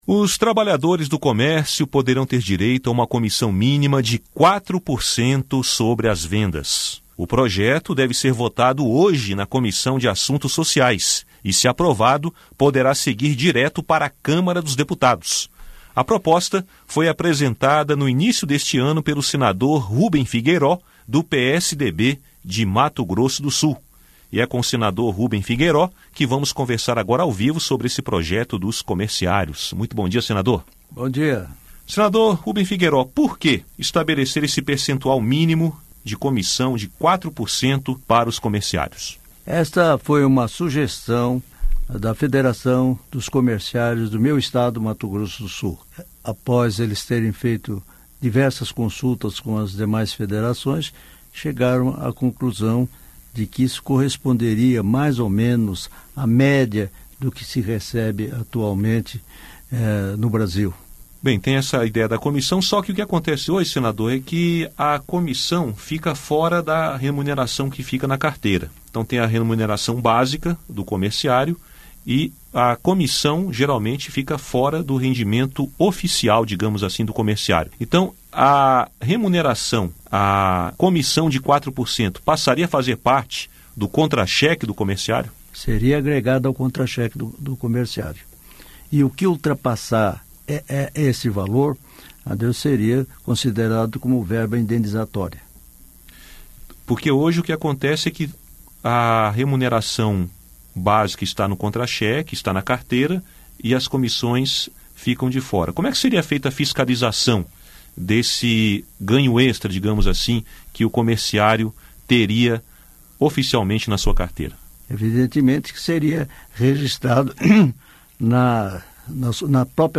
Entrevista com o autor da proposta, o senador Ruben Figueiró (PSDB-MS).